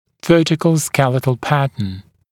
[‘vɜːtɪkl ‘skelɪtl ‘pætn][‘вё:тикл ‘скелитл ‘пэтн]вертикальный скелетный тип